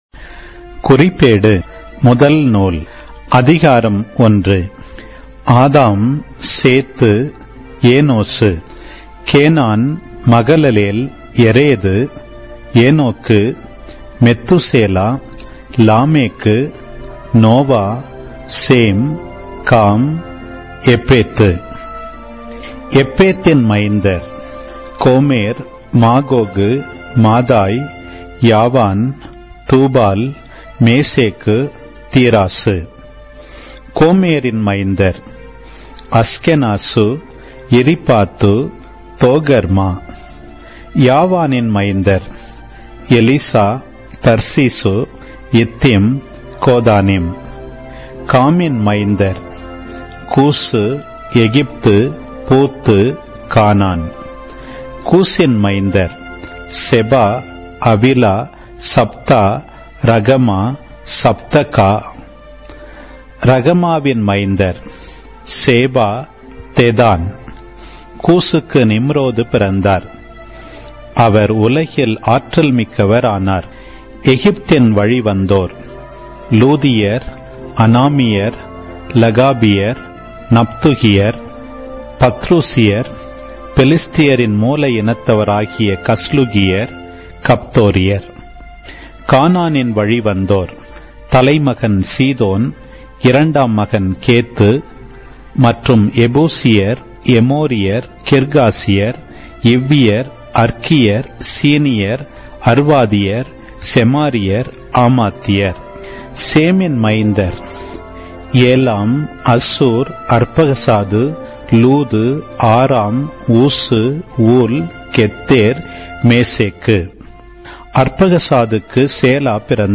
Audio Bible